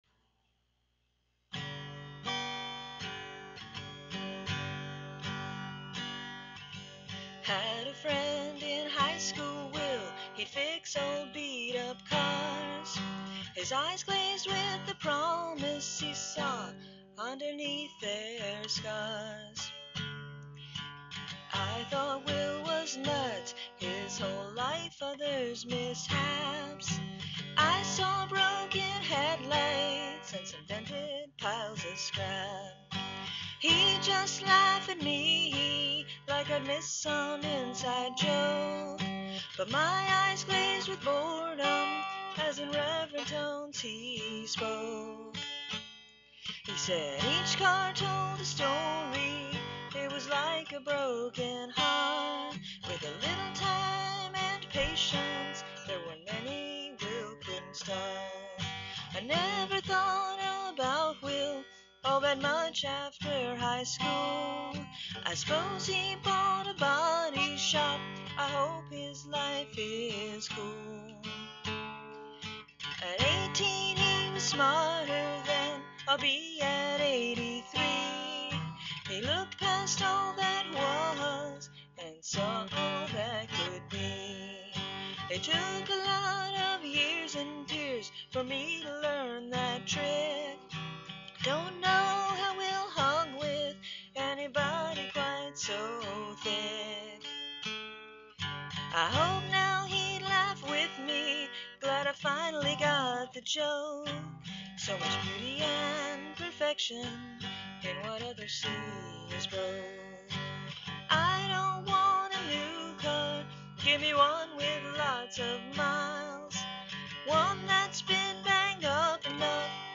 and guitar work